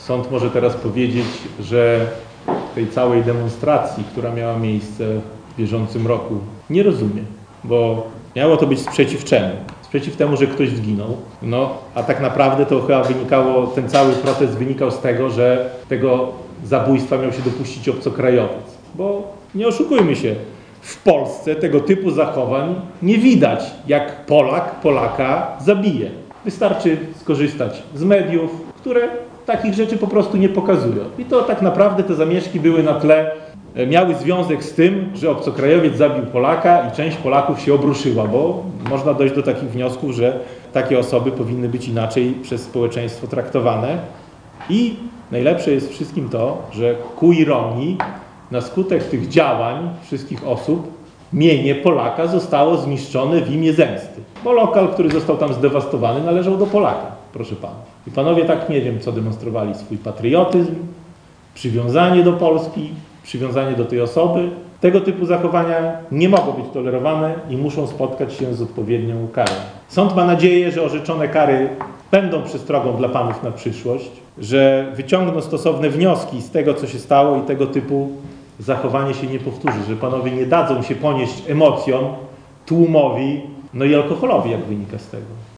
Sędzia Piotr Dawidowicz w uzasadnieniu wyroku wyraził swoje niezrozumienie dla zachowania oskarżonych podczas noworocznych zamieszek.